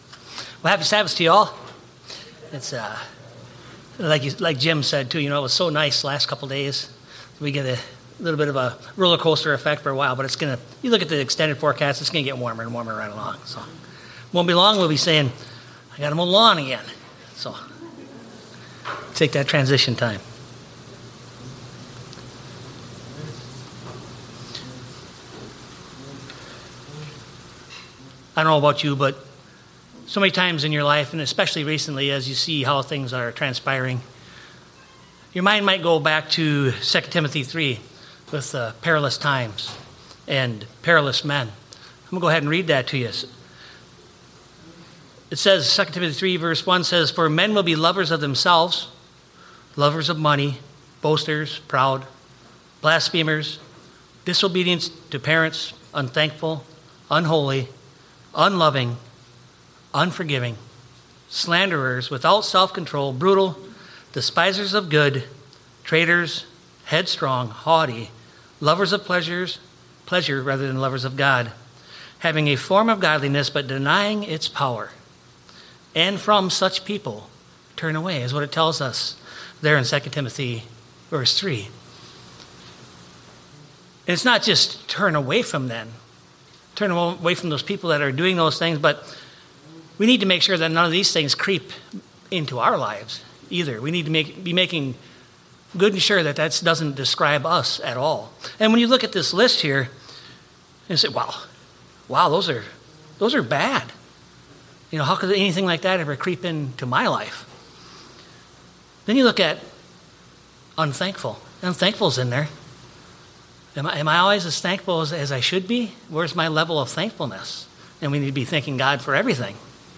Sermons
Given in Eau Claire, WI